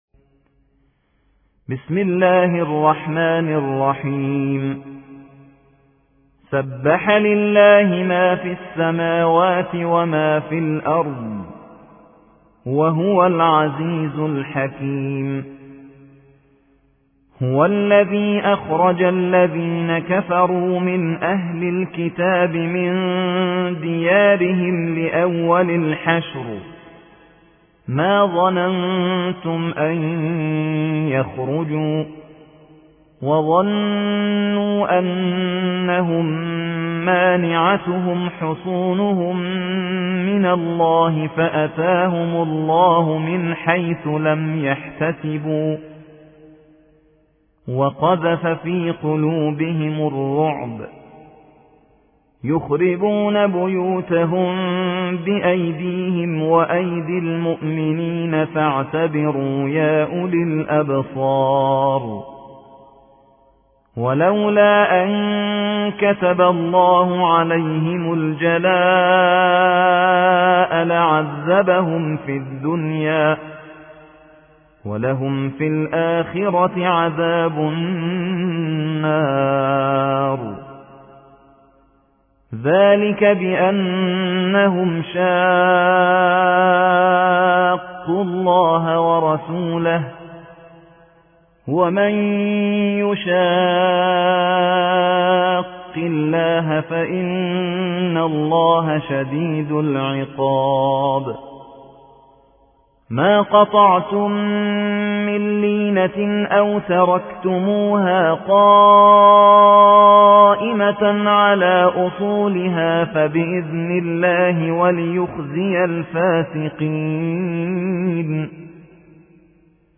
59. سورة الحشر / القارئ